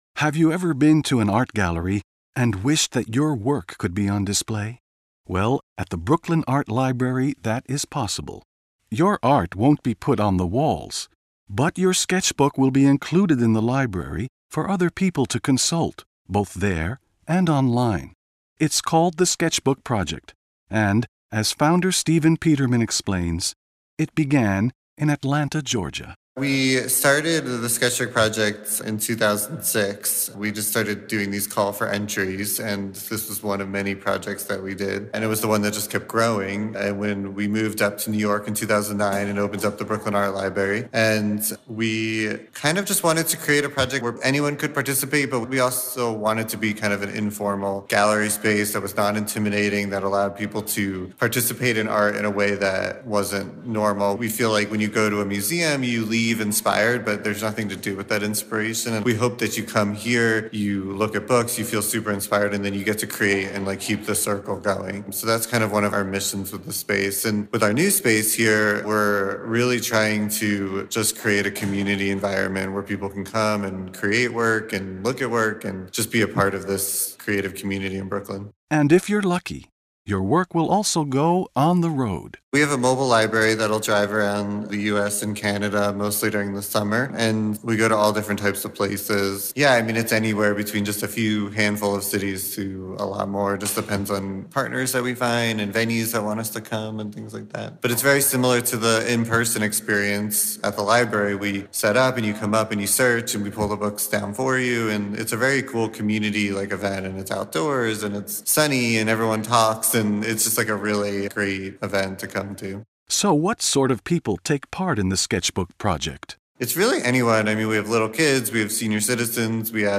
standard American accent